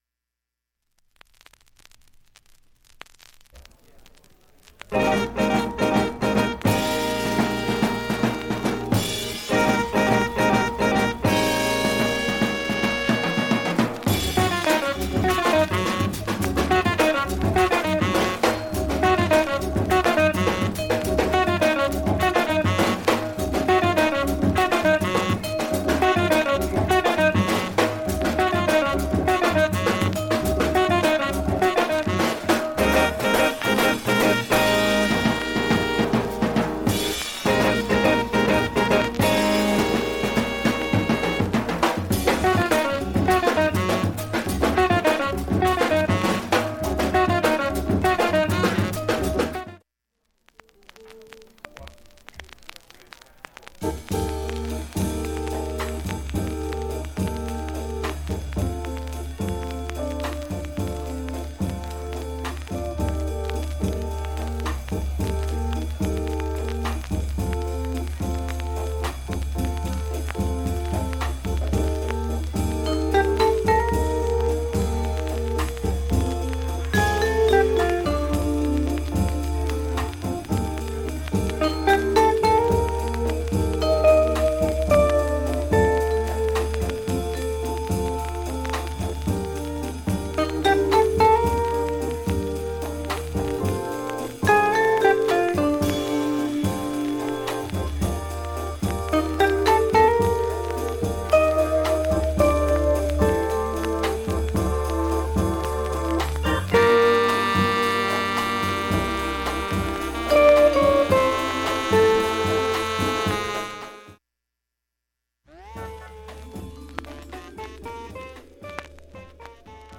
静かな部でチリ出ますが
ライブ盤なのでプツ等も目立たない感じです。
B-1始め喋りの部で軽いチリ出ます。